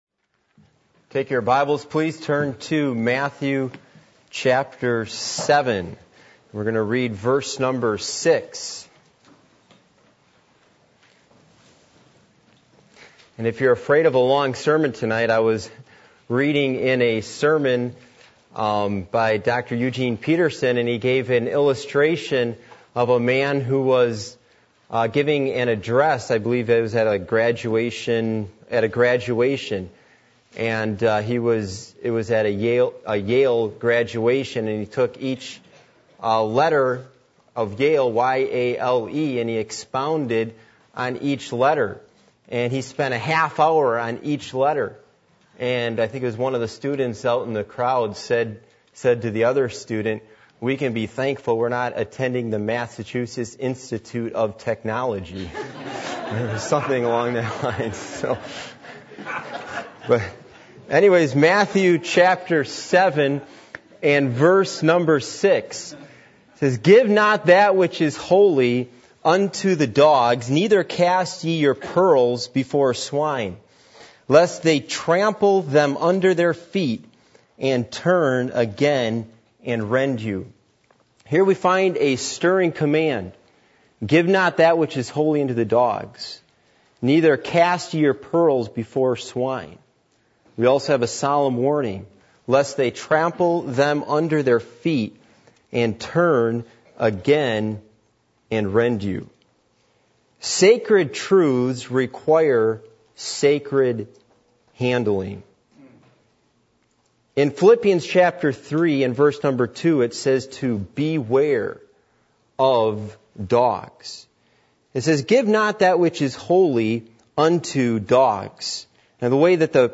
Passage: Matthew 7:6 Service Type: Midweek Meeting %todo_render% « Do You Have A Goal For Your Life?